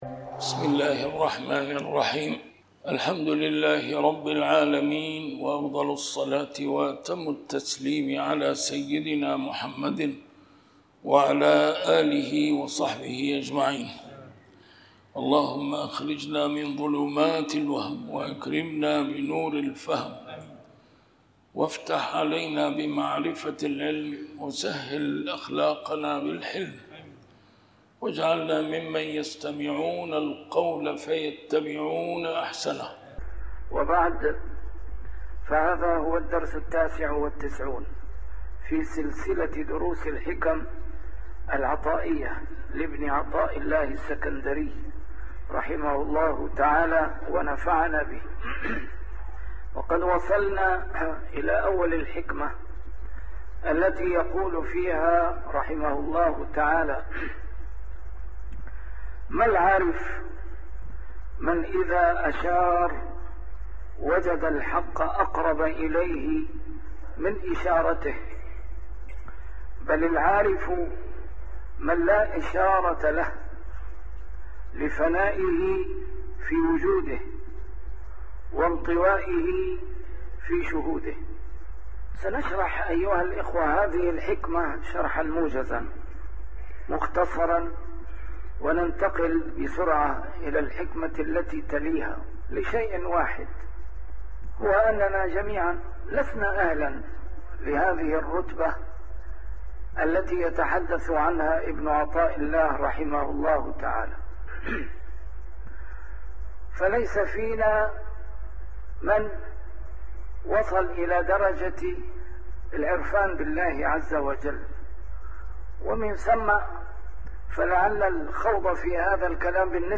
الدرس رقم 99 شرح الحكمة 77+78